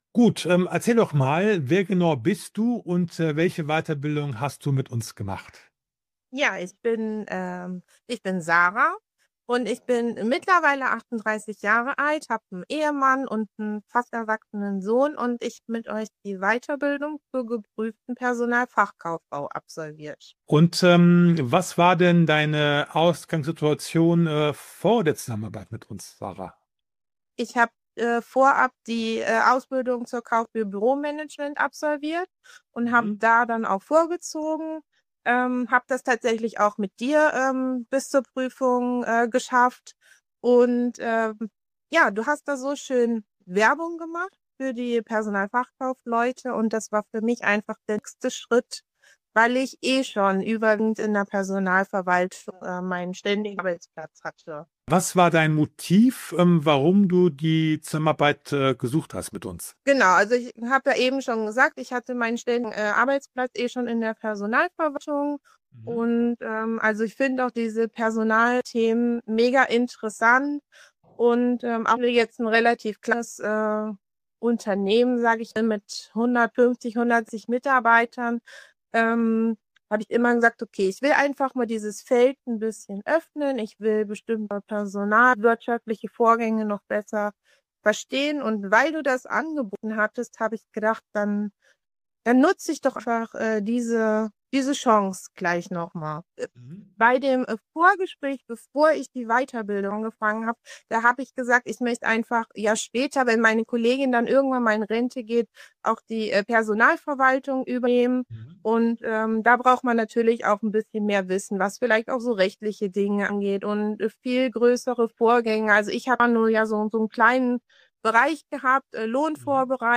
Im Interview erzählt sie: Warum sie sich bewusst für den